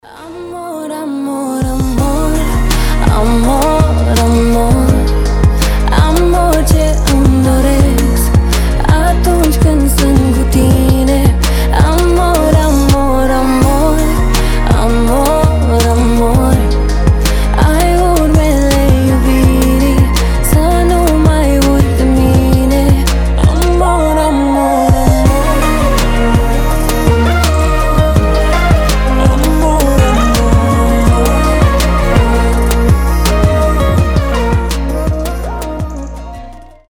поп , красивые
женский голос